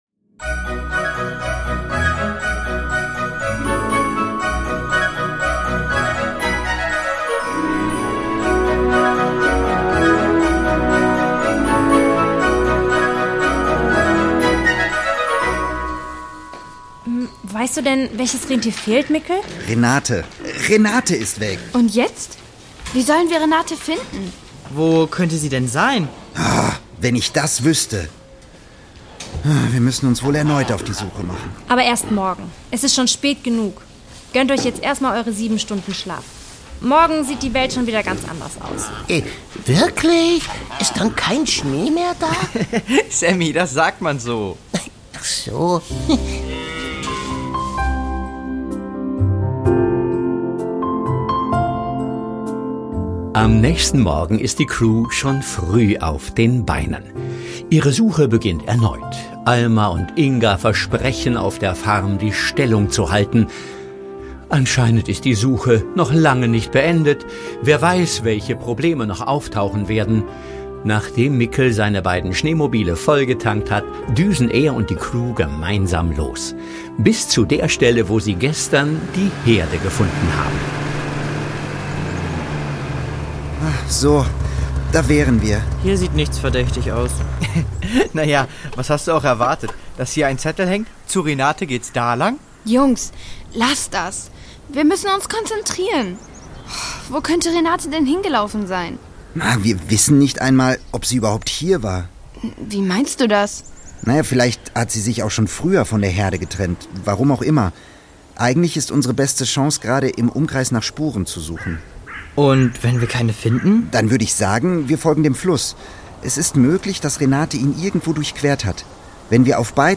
Download - USA 2: Betrug im Museum (1/3) | Die Doppeldecker Crew | Hörspiel für Kinder (Hörbuch) | Podbean